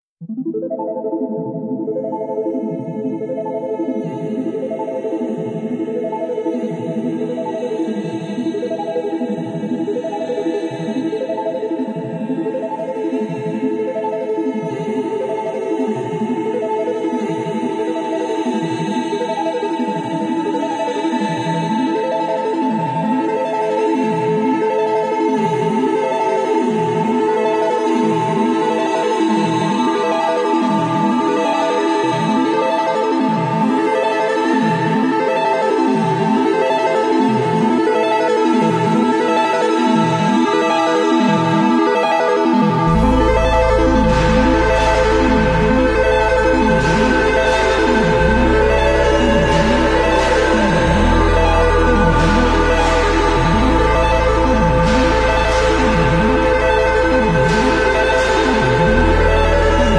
Relax And Enjoy Some ASMR Sound Effects Free Download